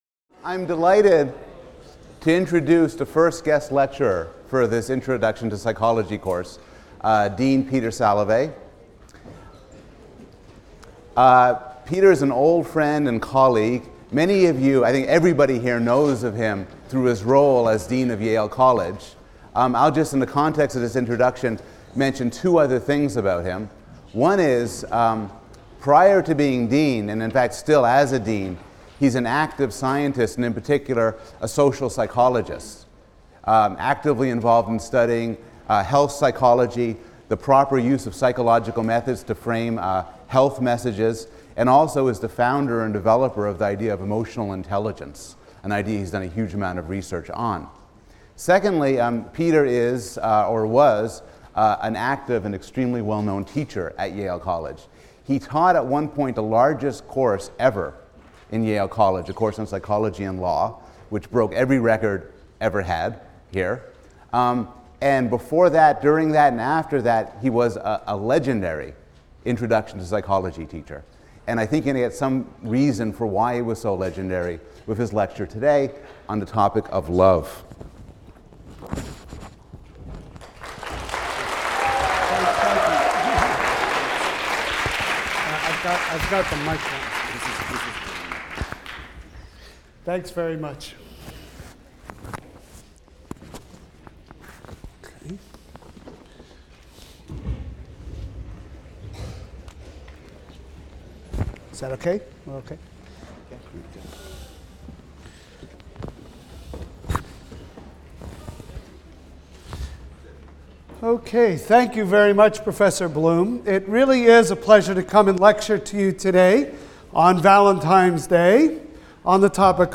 PSYC 110 - Lecture 9 - Evolution, Emotion, and Reason: Love (Guest Lecture by Professor Peter Salovey) | Open Yale Courses